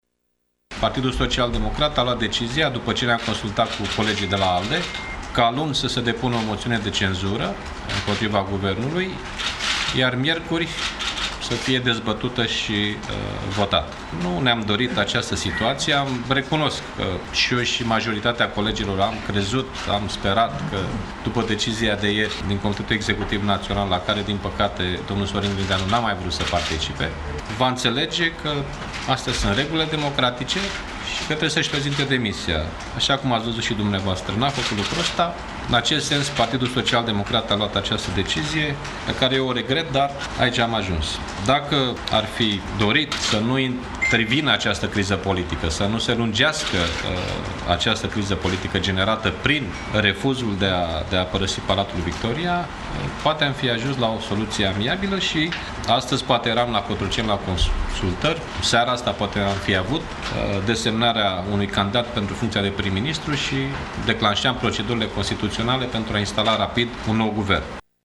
Anunţul a fost făcut de preşedintele PSD, Liviu Dragnea, într-o conferinţă de presă, la finalul şedinţei Comitetului Executiv Naţional: